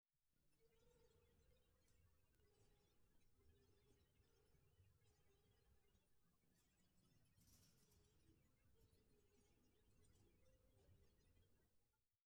Metal_75.wav